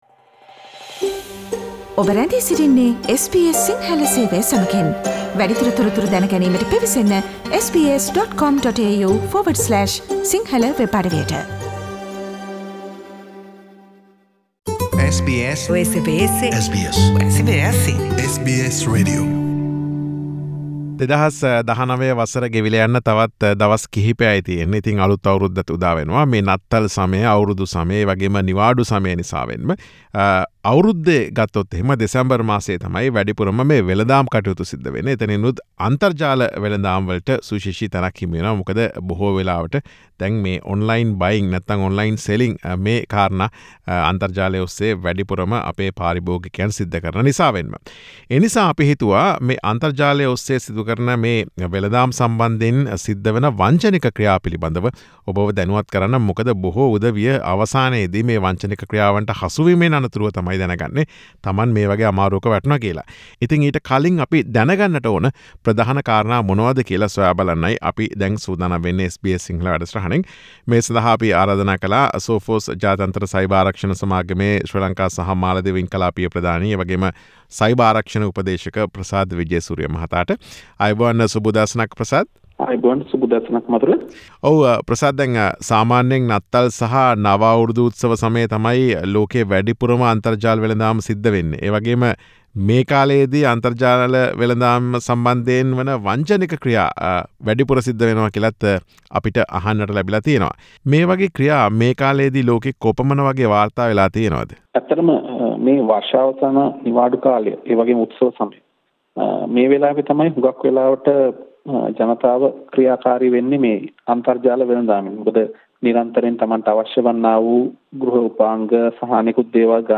සාකච්ඡාව.